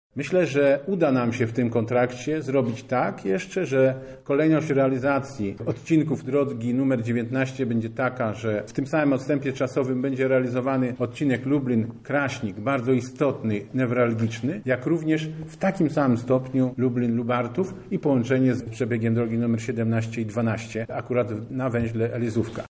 O szczegółach związanych z drogami ekspresowymi S-17 i S-19 mówi Sławomir Sosnowski marszałek województwa lubelskiego.